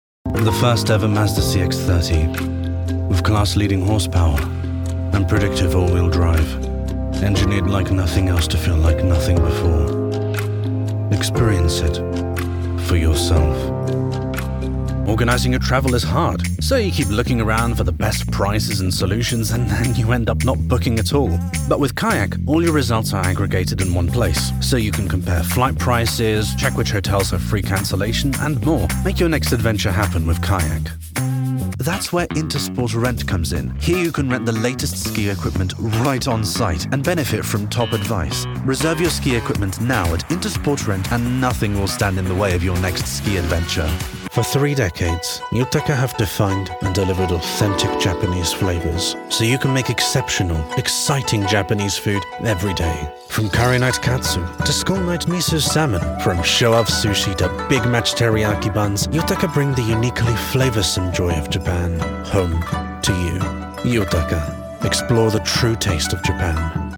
Versatile English voice over, ranging from warm and engaging to theatrical, funny or conversational.
Sprechprobe: Werbung (Muttersprache):